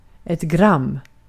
Ääntäminen
Ääntäminen Haettu sana löytyi näillä lähdekielillä: ruotsi Käännös Ääninäyte Substantiivit 1. gram US 2. gramme Artikkeli: ett .